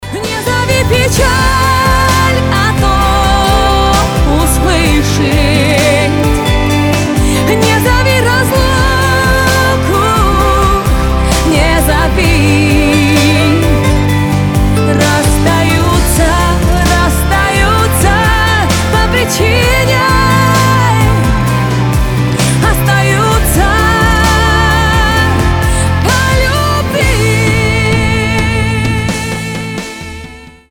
красивые
женский вокал
сильные